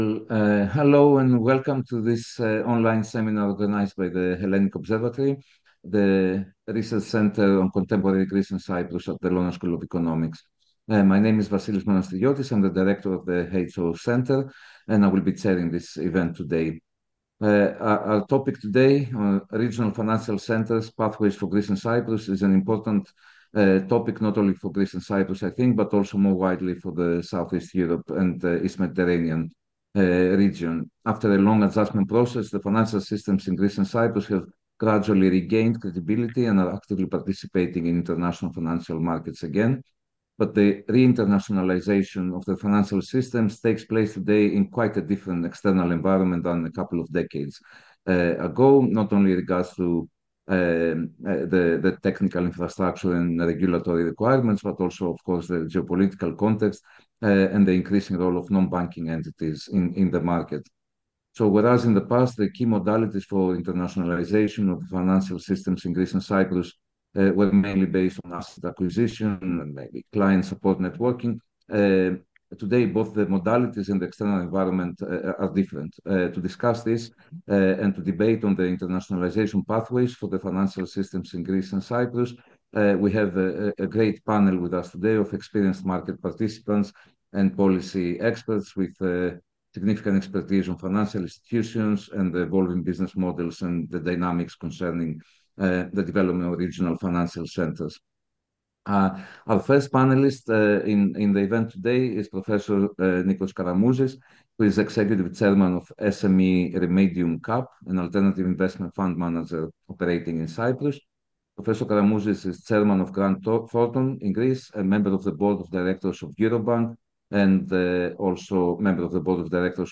See all upcoming public lectures and seminars at the LSE European Institute.